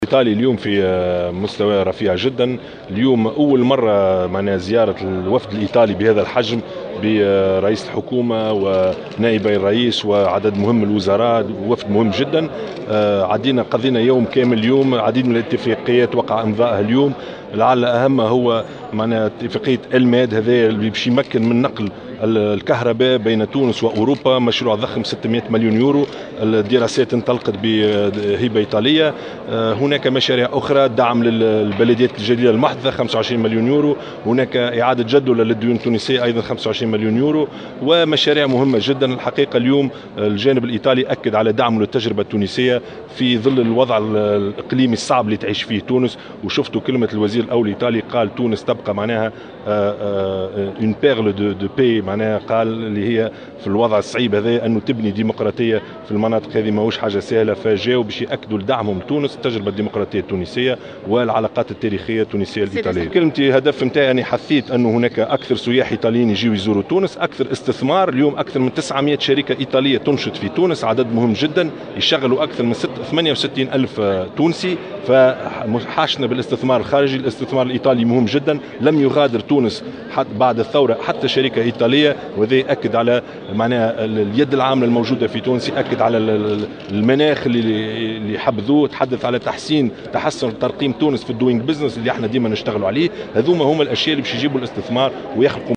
وقال في تصريح لمراسلة "الجوهرة أف أم" على هامش ندوة صحفيّة مشتركة بين الوزيرين، إن أهم هذه الاتفاقيات تلك المتعلّقة باتفاقية نقل الكهرباء بين تونس وأوروبا من خلال مشروع ضخم بقيمة 600 مليون يورو، مشيرا إلى أن الدراسات بخصوص هذا المشروع انطلقت بهبة ايطالية.